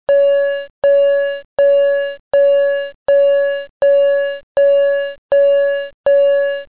Electronic School Bell
alert bell chime ding microphone pa ping ring sound effect free sound royalty free Sound Effects